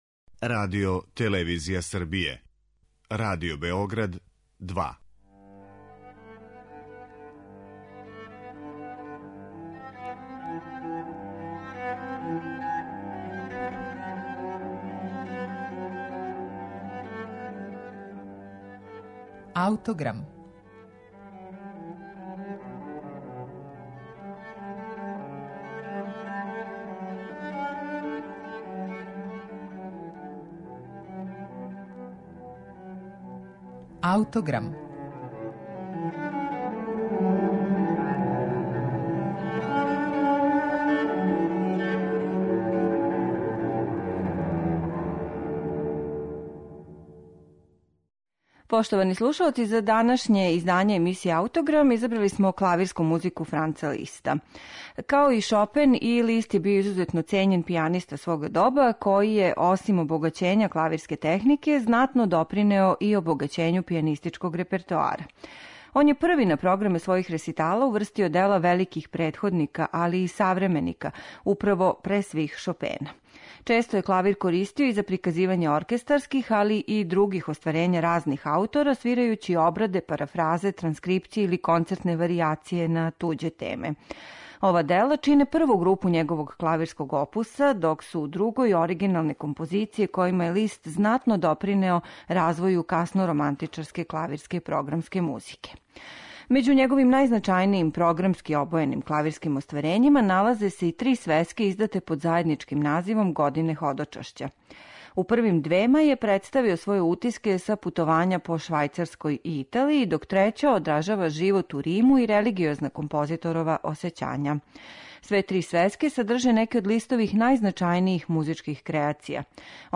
Емисија је посвећена Првој свесци 'Година ходочашћа', збирци клавирских комада Франца Листа.
Ово ремек-дело клавирске литературе ћете слушати у интерпретацији Алфреда Брендела.